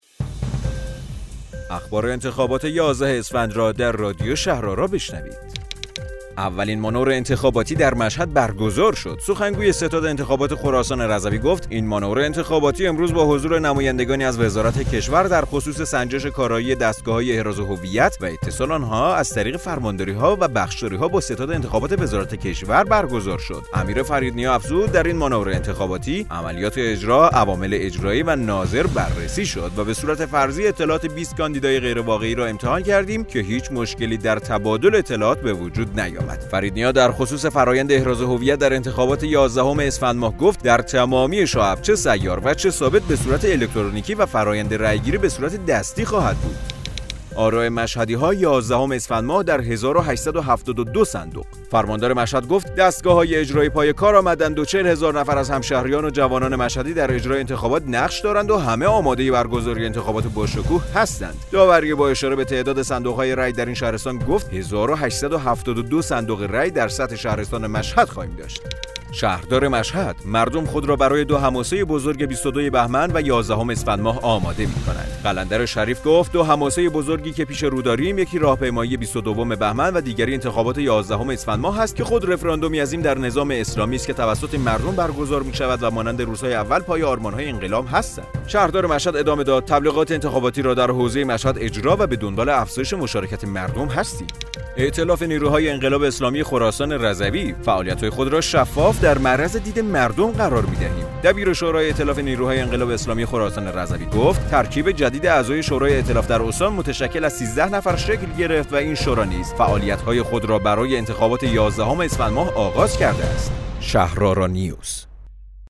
رادیو شهرآرا، پادکست خبری انتخابات یازدهم اسفندماه است.